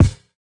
003_Kick - Brooklyn Feels.wav